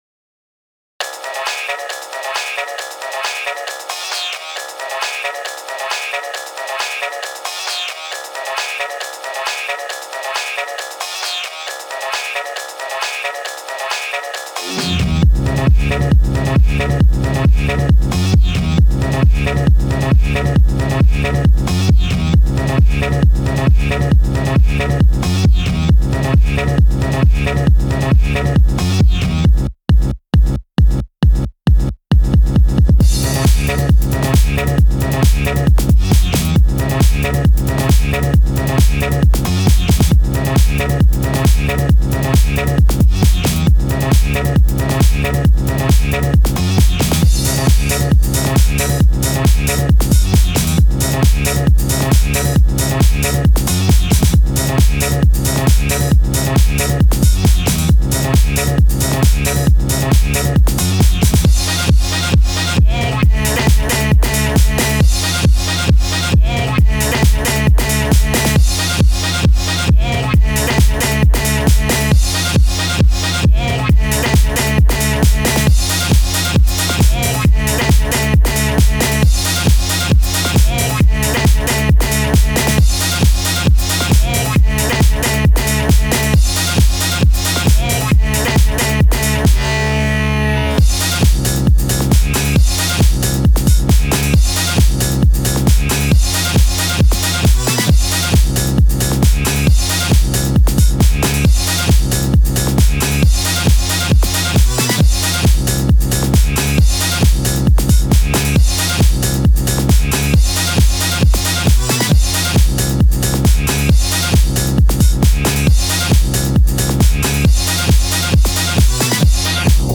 Genre House